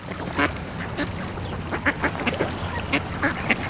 duck.mp3